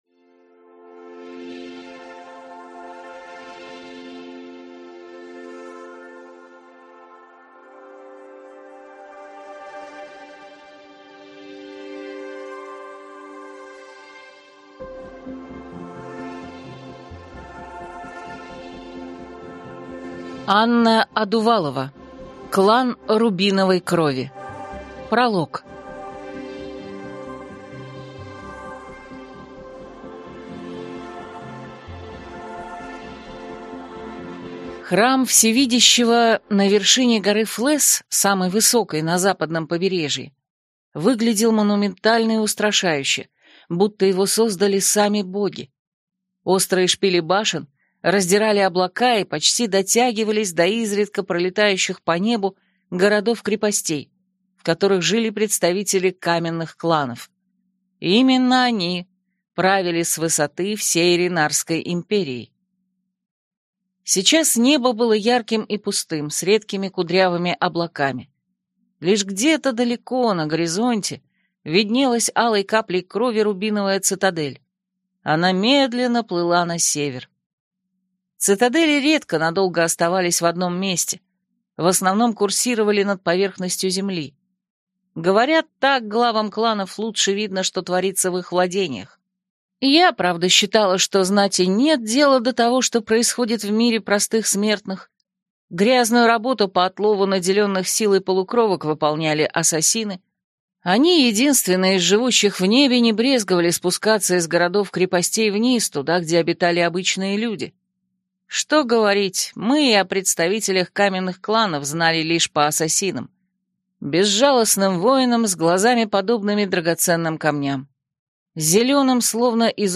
Аудиокнига Клан рубиновой крови | Библиотека аудиокниг